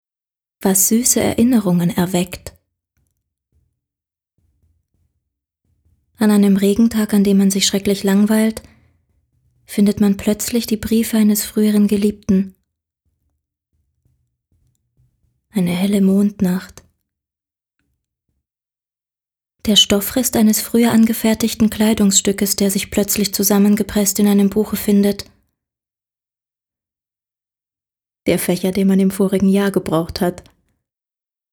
deutsche Sprecherin, ausgebildete Schauspielerin.
Sprechprobe: Industrie (Muttersprache):
german female voice over artist